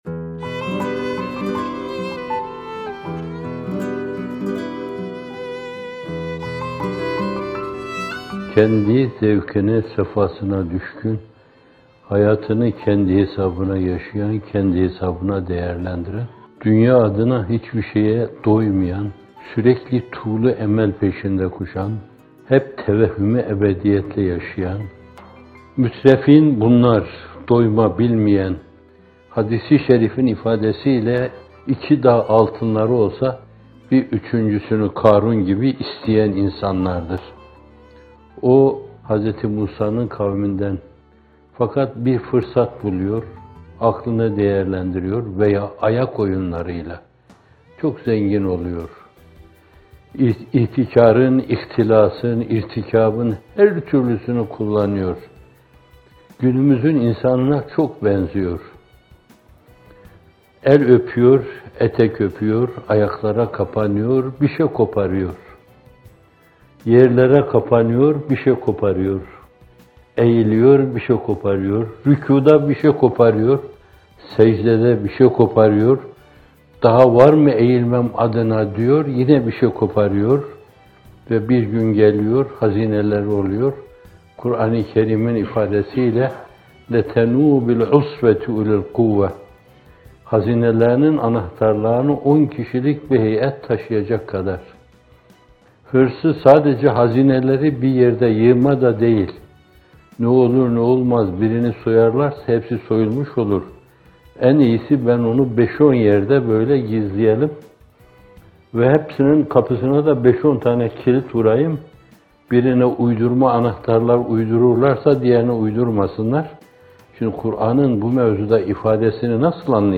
Bir Nefes (68) – Mütrefîn: Dünyalığa Doymayanlar - Fethullah Gülen Hocaefendi'nin Sohbetleri